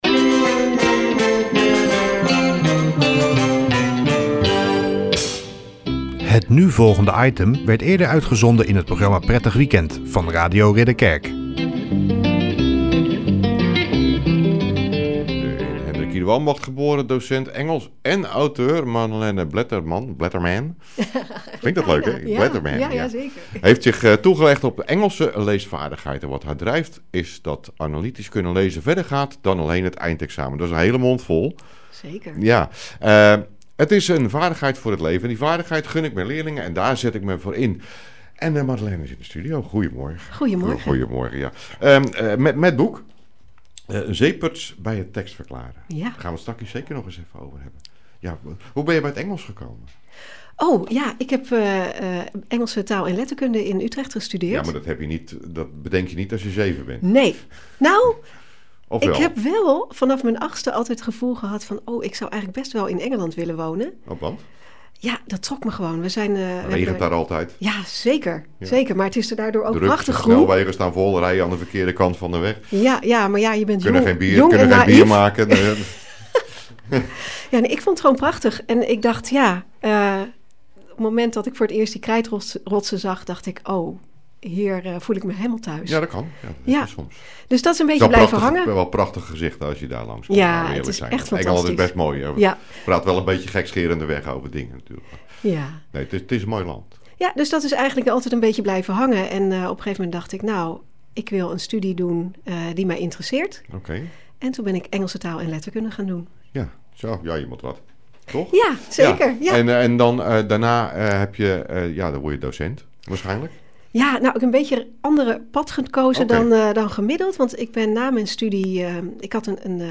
Radio interview op 1 juni 2024 Radio Ridderkerk